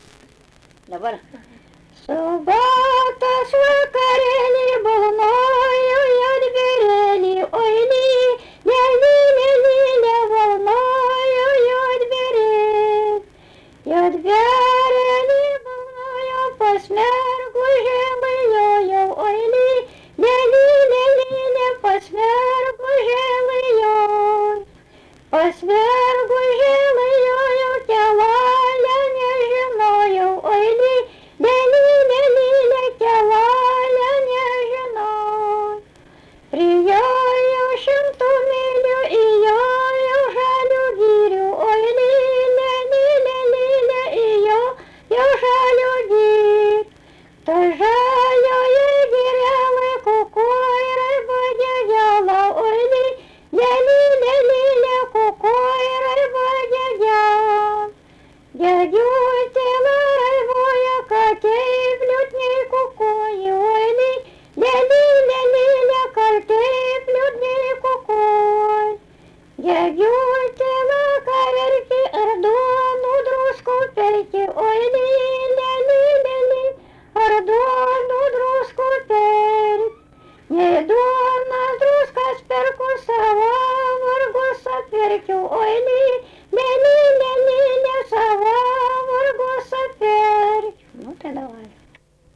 daina
Atlikimo pubūdis vokalinis